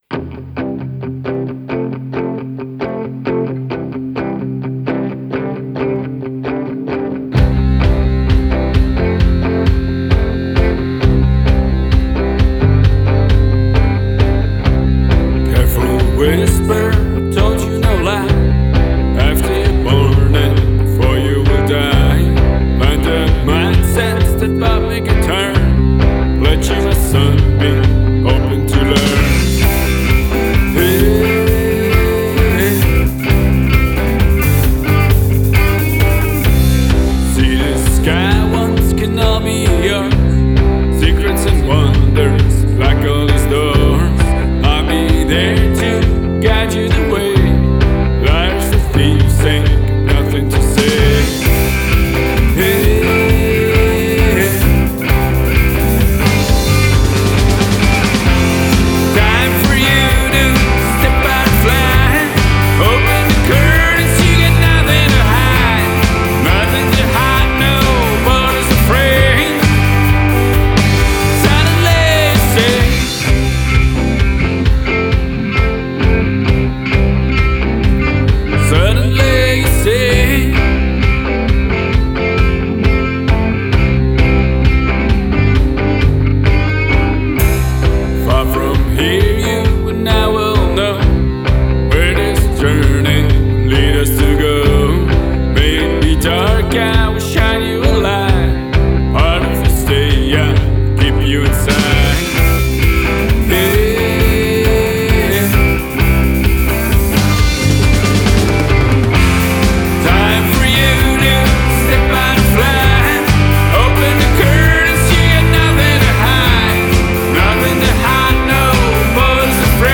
Alternative Rock Band needs a CD cover